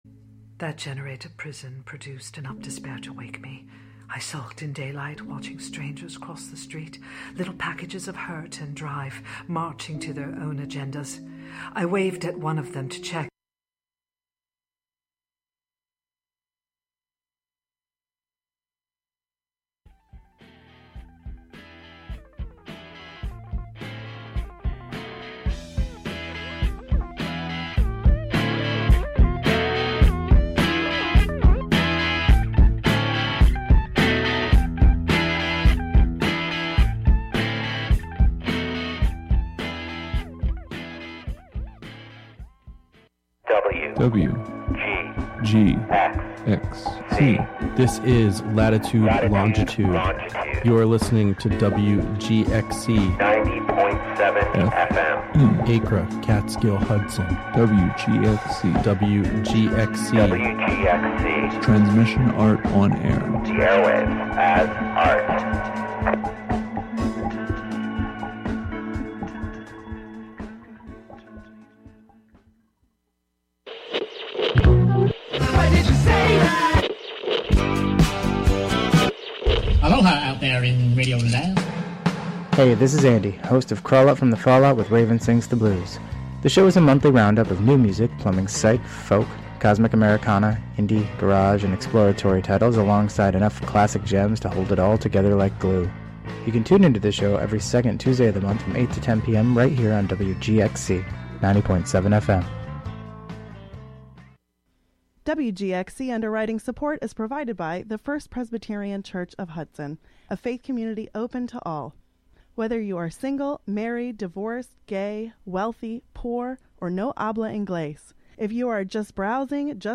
Counting down ten new sounds, stories, or songs, "American Top 40"-style.